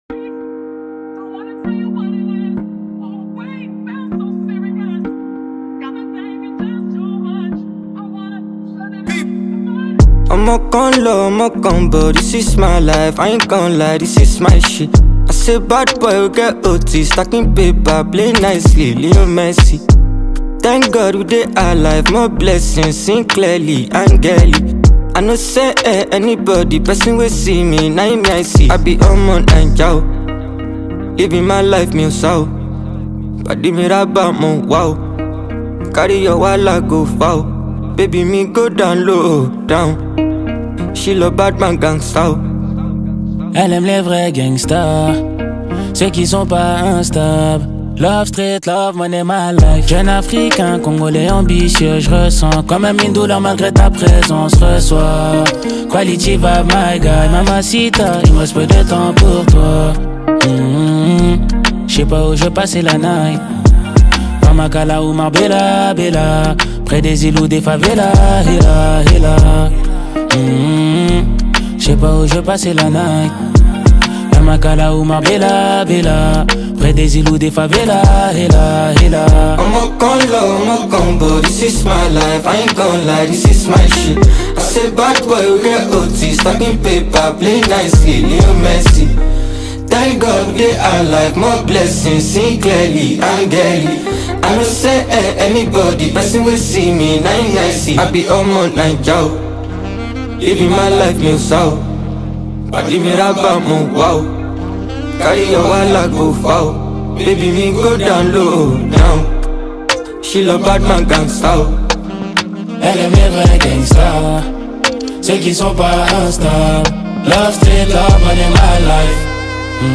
French-born Congolese singer/ rapper
cool and dope House-infused banger